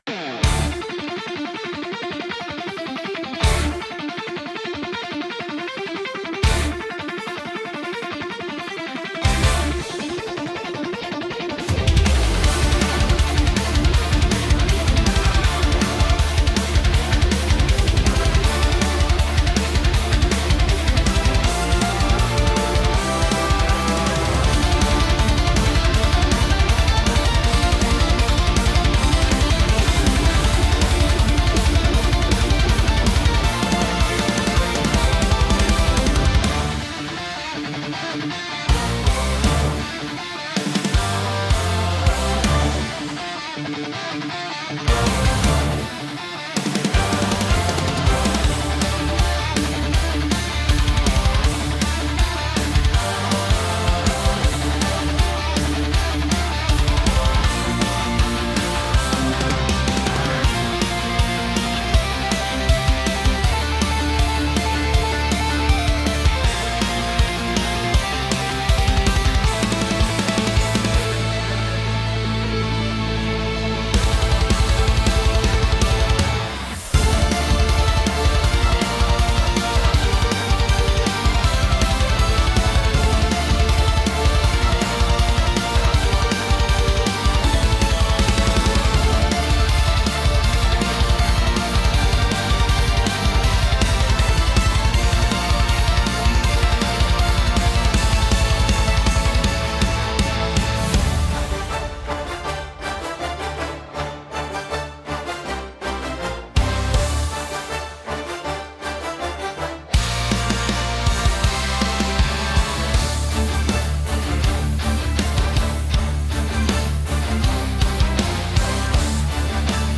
ゲームの戦闘シーン風BGM
Instrumental only. No vocals.
Epic orchestral rock instrumental for a dramatic battle scene. Fast tempo around 160 BPM. Electric guitars, full orchestra, brass, strings, and powerful drums. Tense and heroic atmosphere with emotional melodies and driving rhythm.